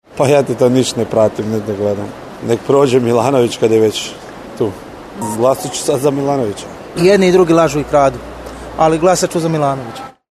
Anketa